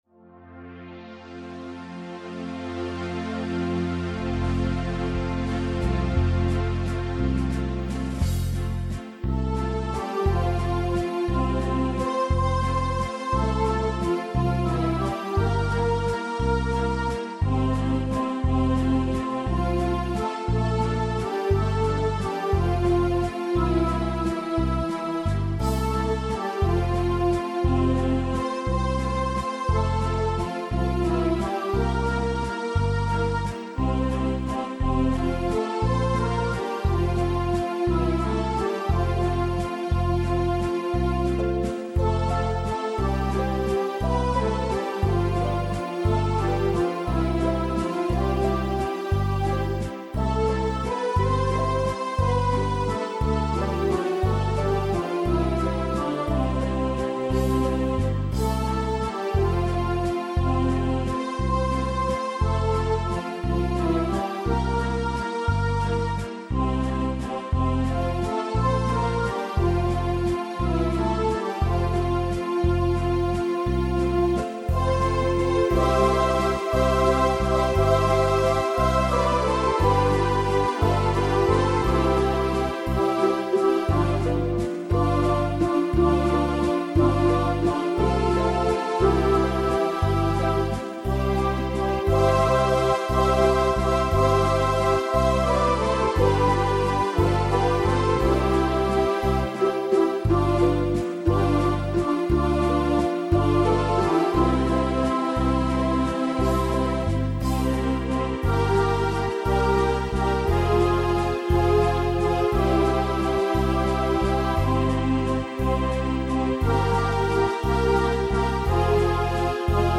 Keyboard und Synthesizer